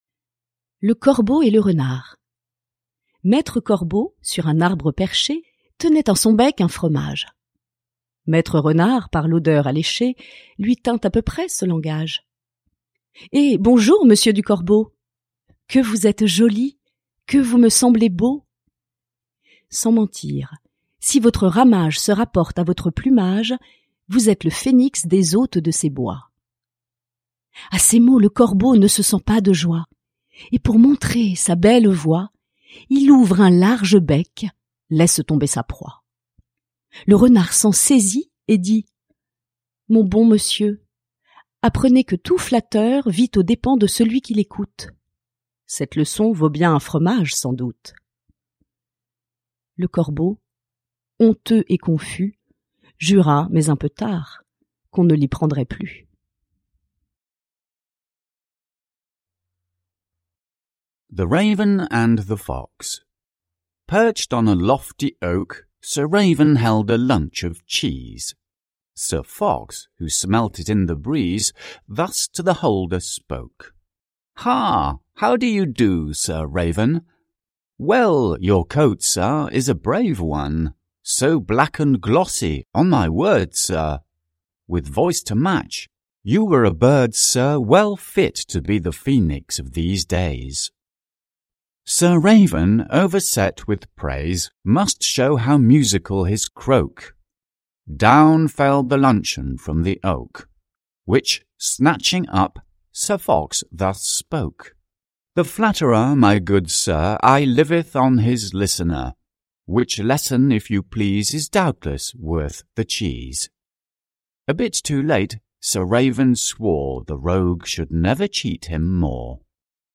Ljudbok
This album focuses exclusively on the well-known Fables de La Fontaine, which every French child knows by heart. You will hear professional comedians reading in a clear and intelligible voice the same fable, first in French, and then in English, for you to compare and to get used to the melody of the words.